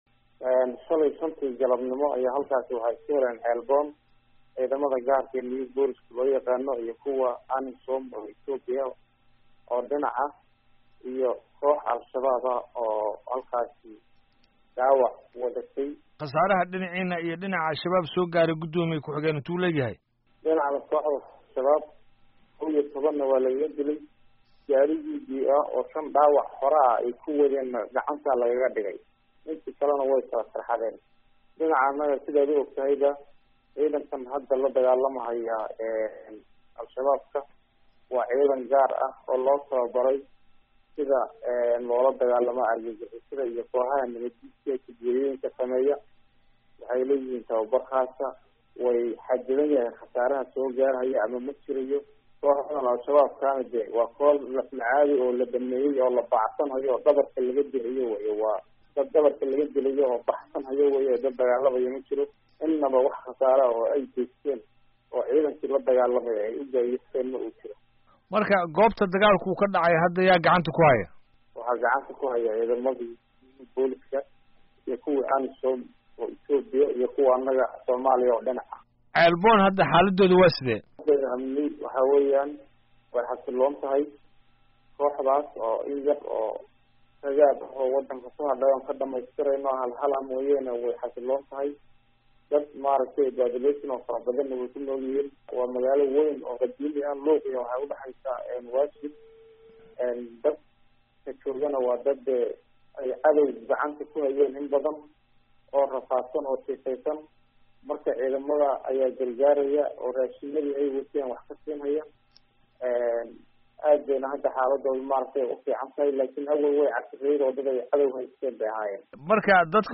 Wareysi: Dagaalka Bakool